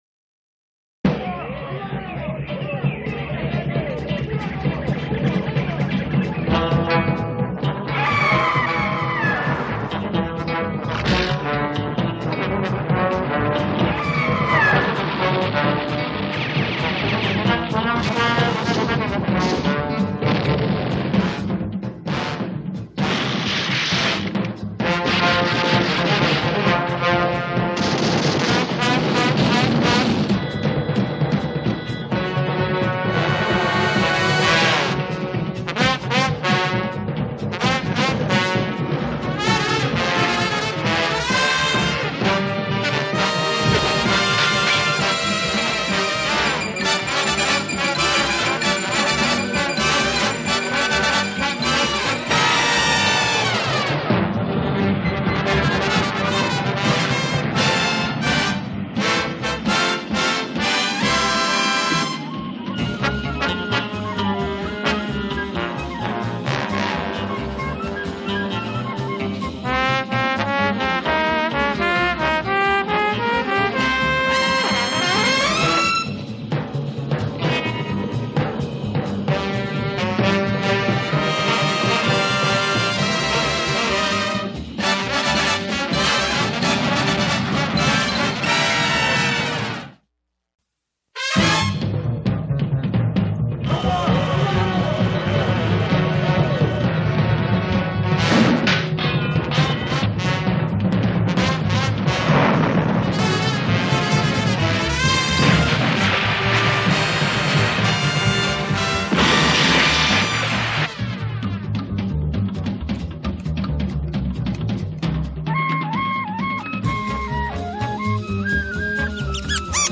Opening & closing themes